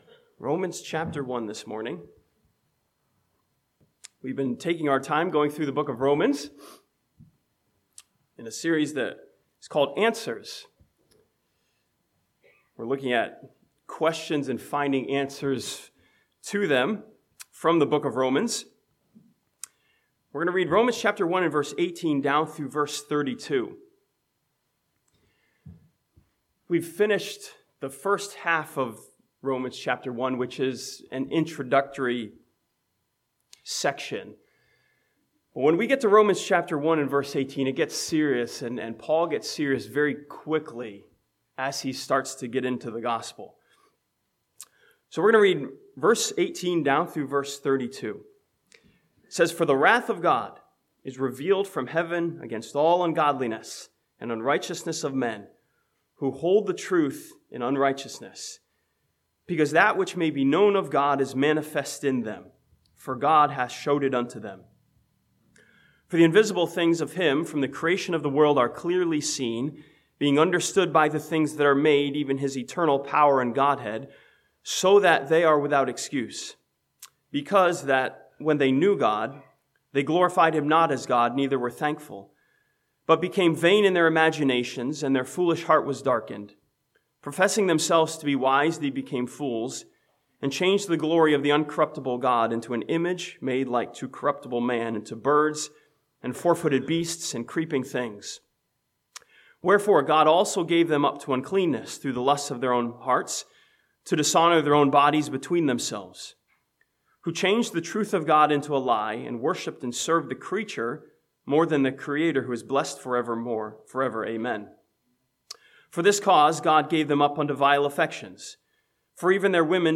This sermon from the end of Romans chapter 1 answers the question of judgment and finds the whole world guilty before God.